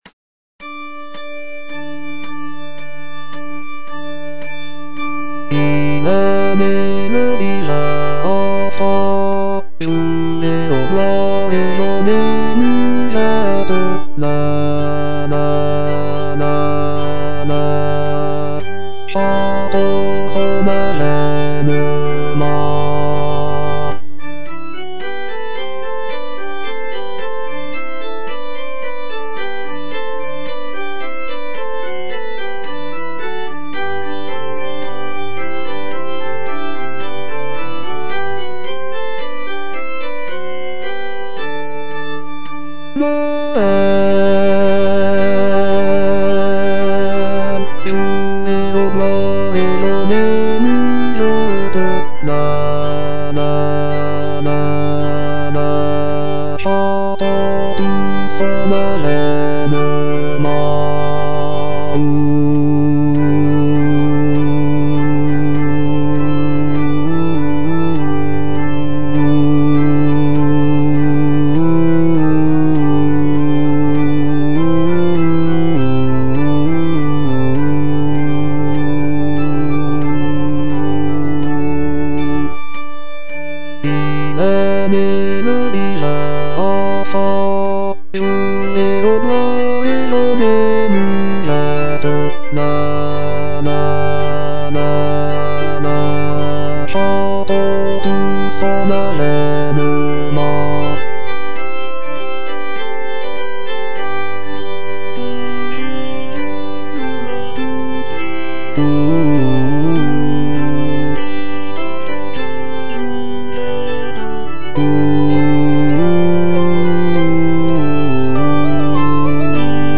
- IL EST NÉ, LE DIVIN ENFANT Bass I (Voice with metronome) Ads stop: auto-stop
It is a joyful and lively song that celebrates the birth of Jesus Christ. The melody is catchy and has been widely popularized, making it a beloved Christmas tune.